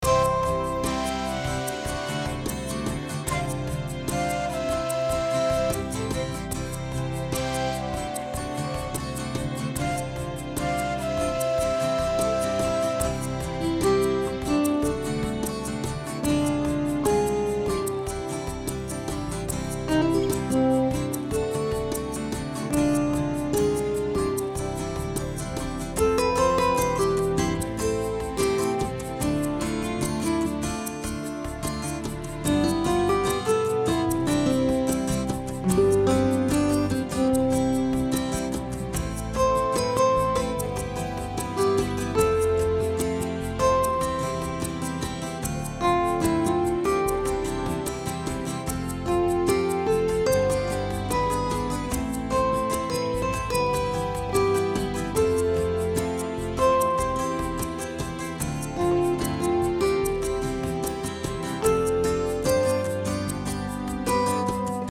Loop Full Score